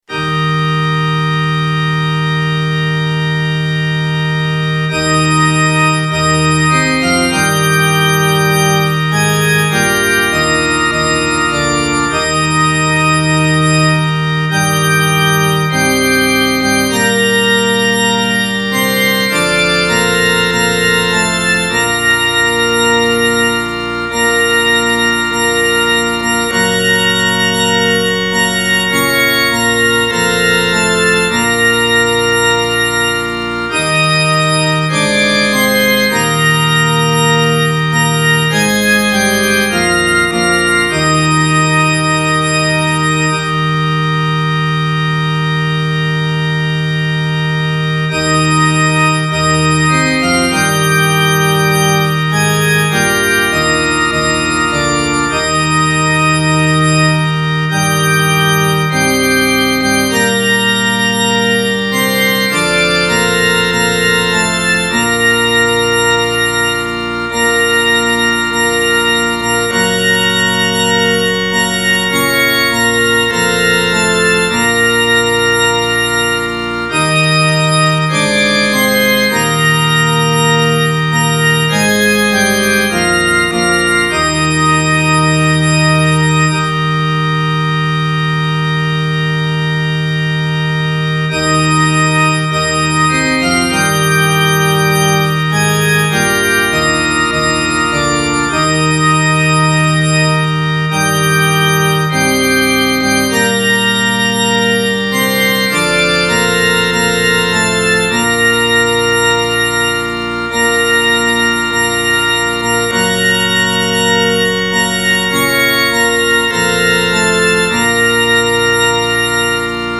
I had terrible trouble getting this one going so I apologise if the backings are less than stellar. I had several goes in several styles so take your pick of these three.